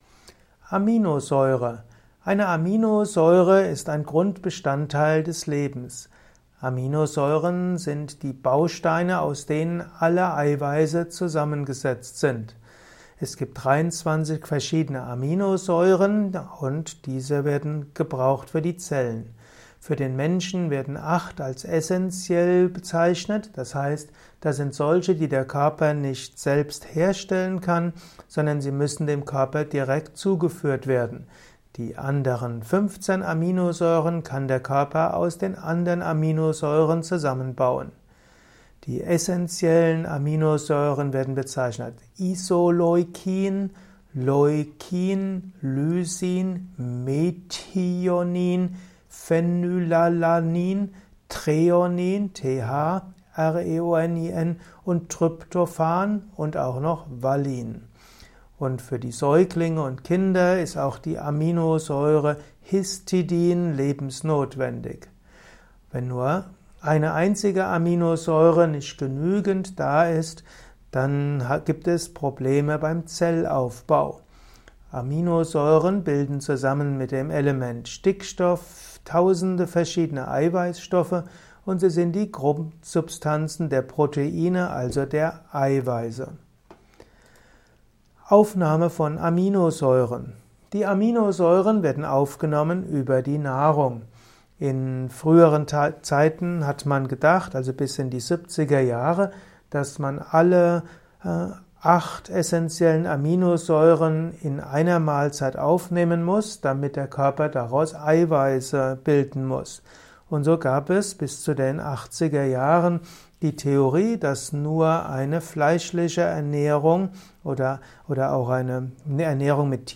Simple und komplexe Infos zu Aminosäuren in diesem Kurzvortrag